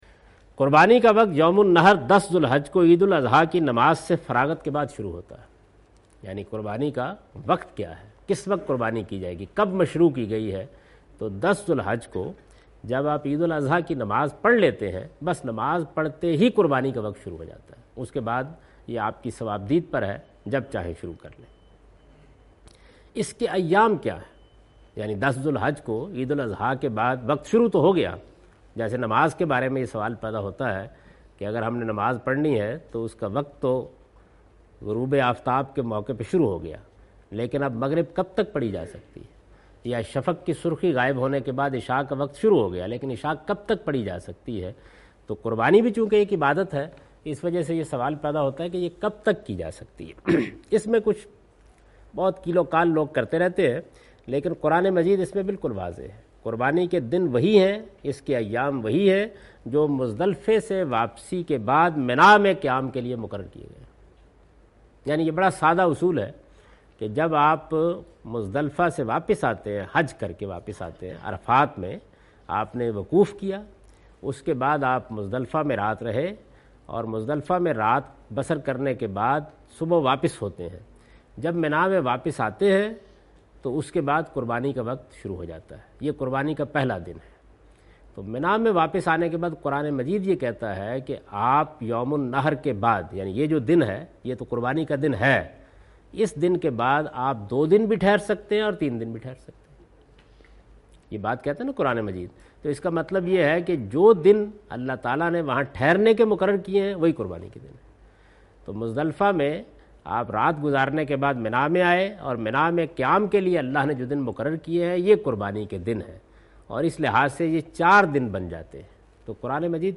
In this video of Hajj and Umrah, Javed Ahmed Ghamdi is talking about "Time of Animal Sacrifice".